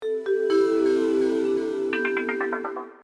Shutdown.mp3